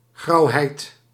Ääntäminen
IPA : /ɡlum/